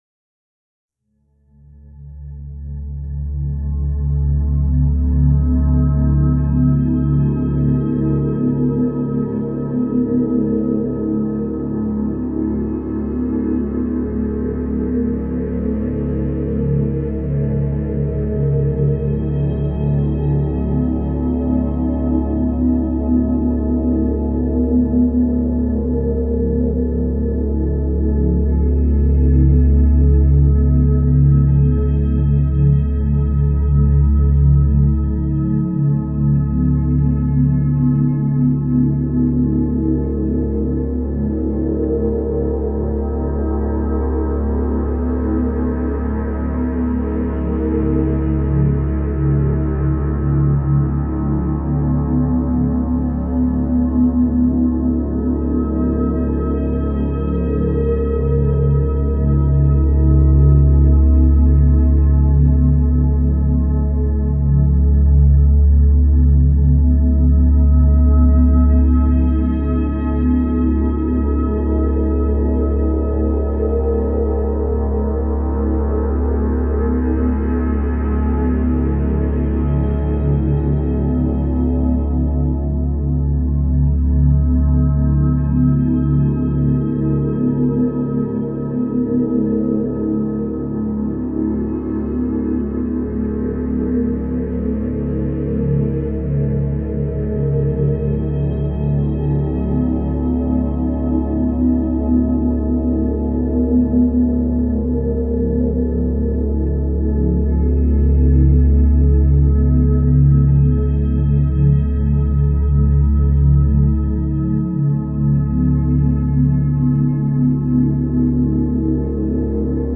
285Hz – فرکانس 285 هرتز
در این فصل می‌خوایم براتون یک سری موسیقی با فرکانس‌های مختلف قرار بدیم که بهشون تون هم می‌گن.
به بعضی از این ها اصطلاحا می‌گن Solfeggio Frequencies که ترجمش فرکانس‌های سلفژی میشه که به فرکانس‌های خاصی اتلاق میشه.
285Hz.mp3